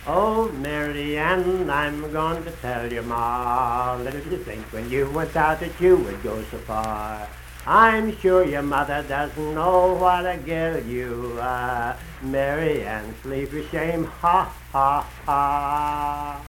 Unaccompanied vocal music
Verse-refrain 1(8). Performed in Hundred, Wetzel County, WV.
Voice (sung)